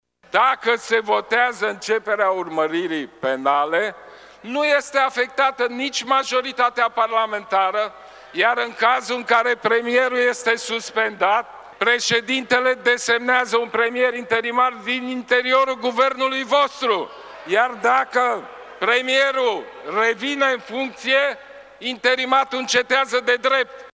Liderul deputaţilor liberali, Ludovic Orban, a spus, în plenul Camerei, că votul de astăzi va afecta democraţia din România, îndemându-şi colegii să voteze în favoarea justiţiei şi să nu o obstrucţioneze: